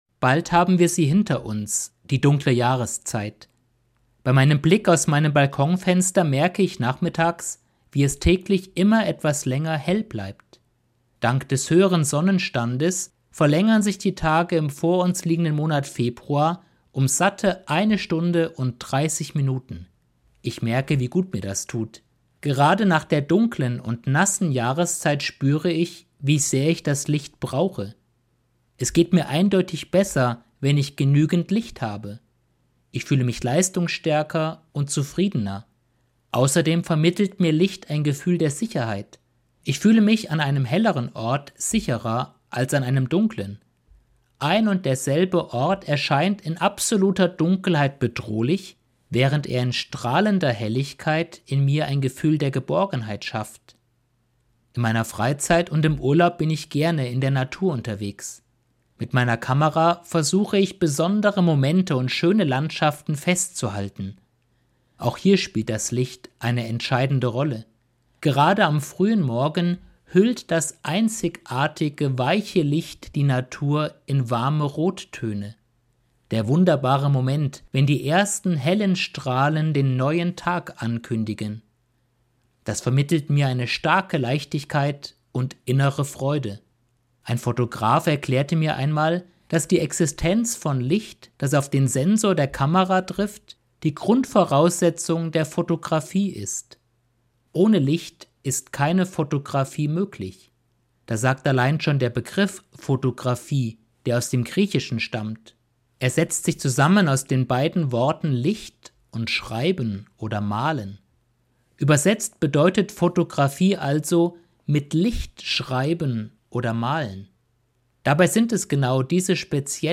Eine Sendung